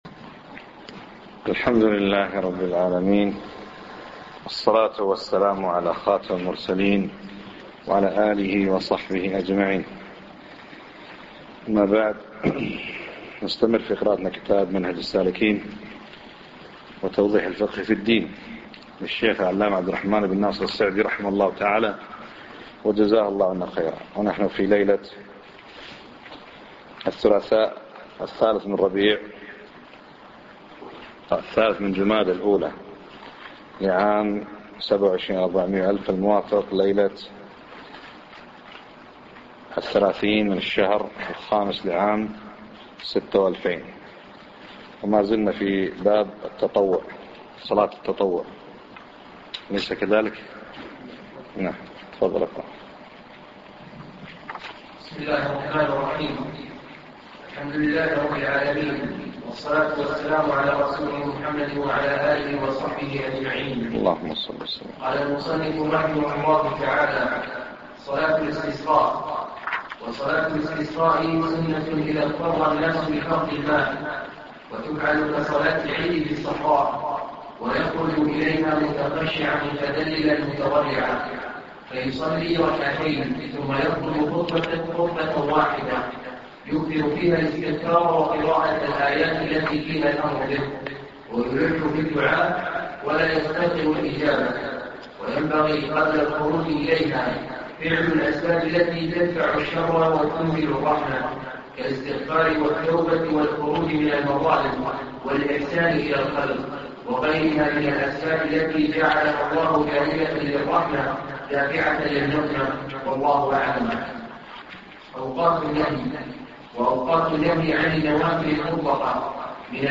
الدرس الخامس و العشرون - منهج السالكين و توضيح الفقه في الدين